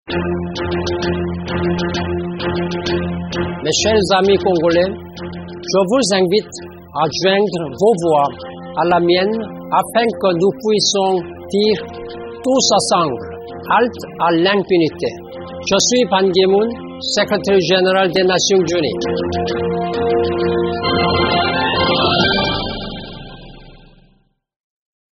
Ecoutez ici les messages de Ban Ki-moon, secréteur général de l’ONU, à l’occasion de la campagne de lutte contre les violences faites aux femmes et aux enfants: